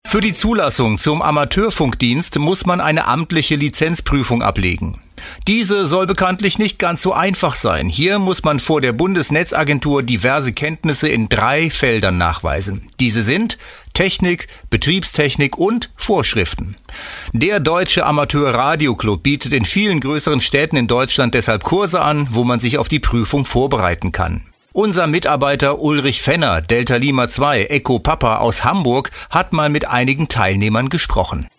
Die Originalaufmahmen der einzelnen Interviewssind hier zu finden:
Anmoderation